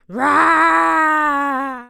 Female_High_Roar_01.wav